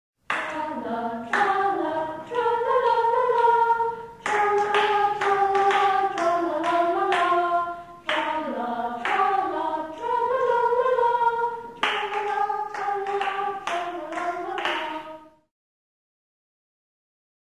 Campfire Songs